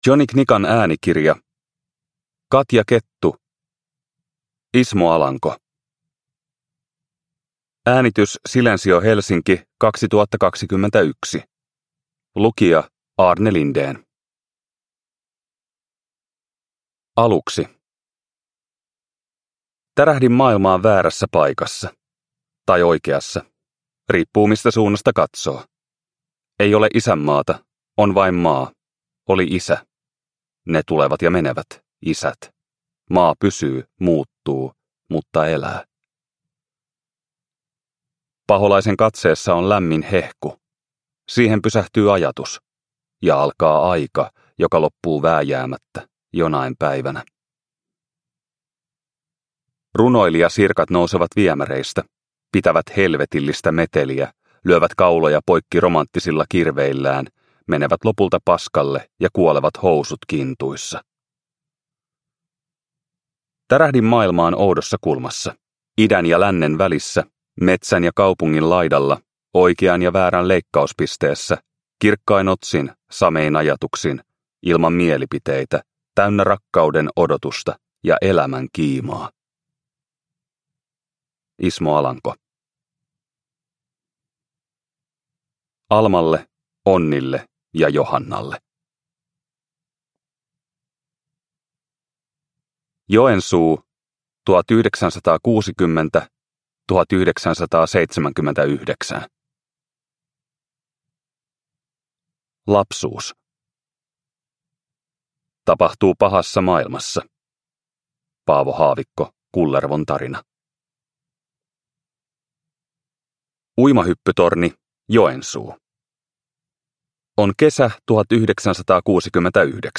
Ismo Alanko – Ljudbok – Laddas ner